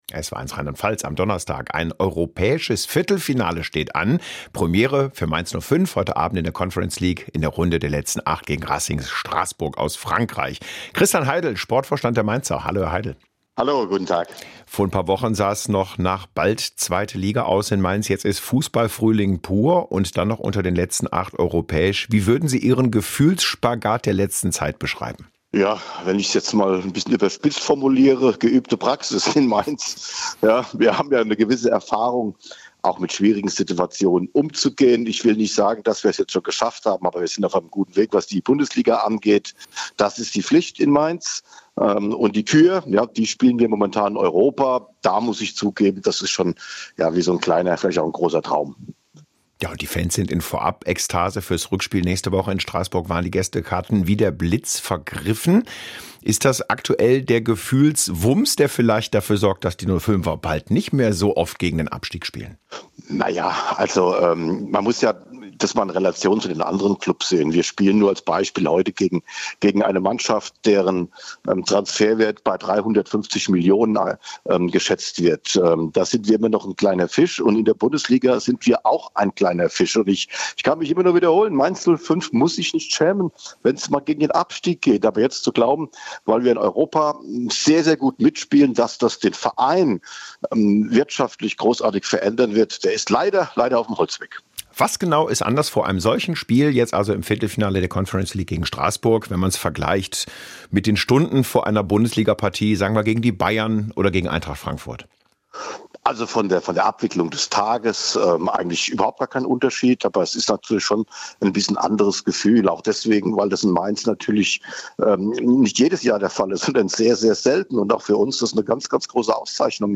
Mainz-05-Boss Christian Heidel im Interview
Interview